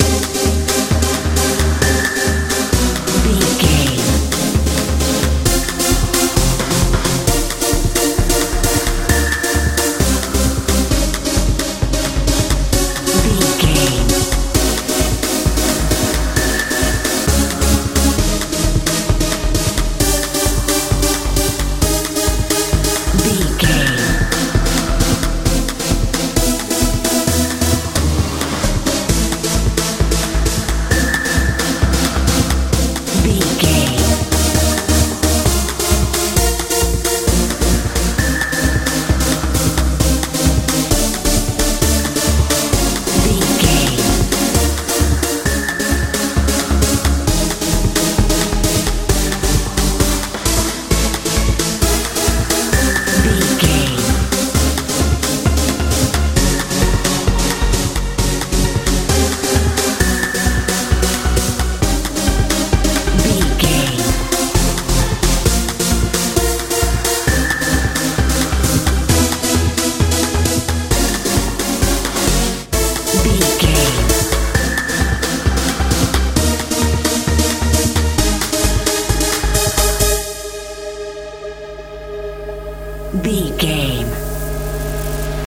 modern dance
Ionian/Major
bass guitar
synthesiser
drums
80s